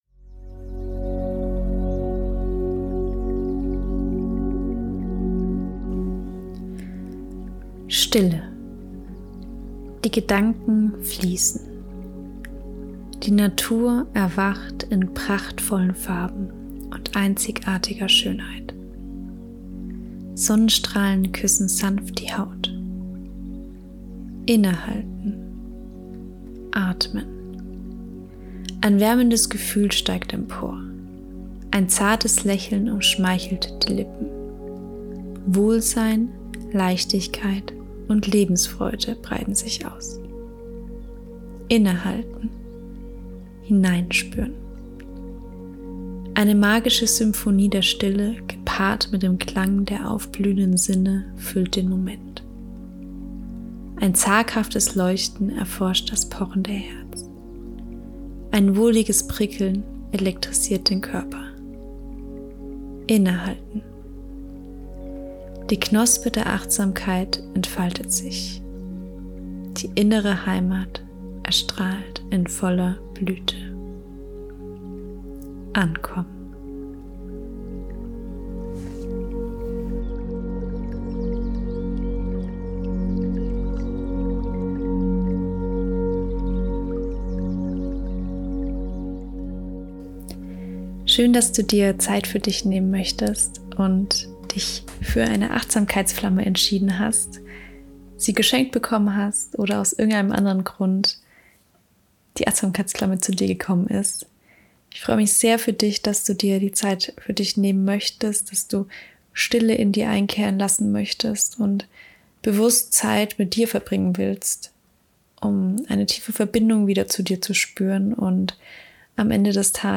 Audioanleitung-Achtsamkeitsflamme-Stille_mixdown.mp3